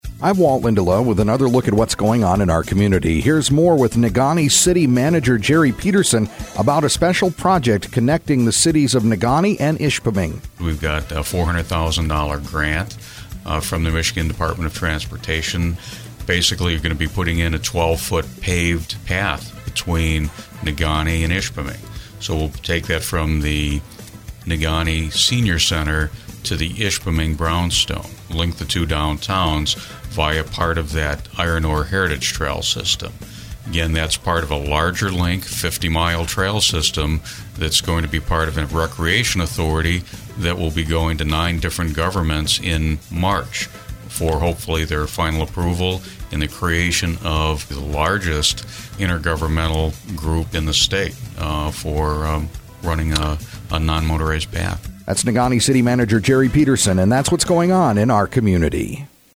INTERVIEW: Gerald Peterson, Negaunee City Manager